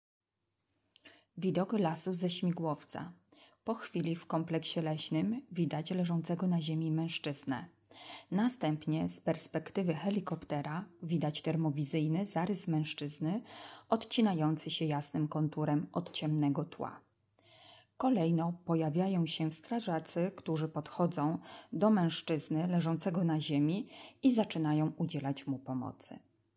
Nagranie audio audiodekskrypcja do filmu Łódzcy policjanci piloci odnajdują zaginionego 81- latka.